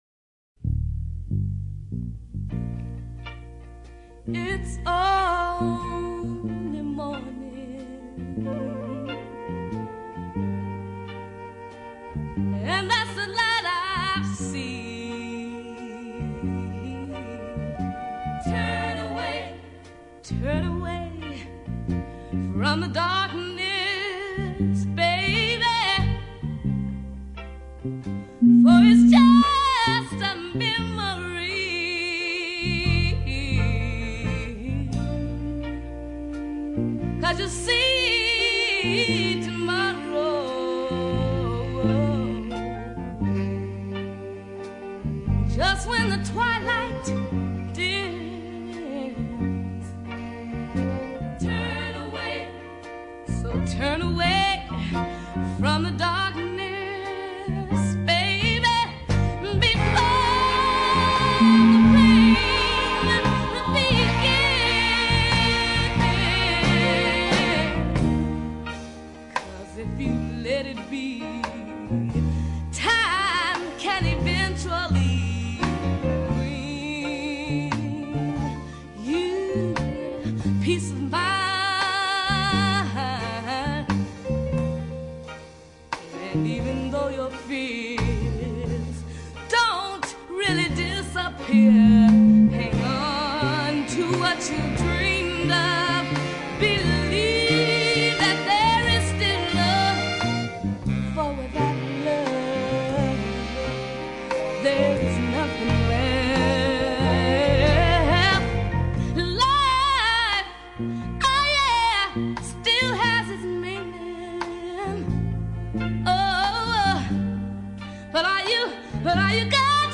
ballad
Her voice is both soulful and crystal clear.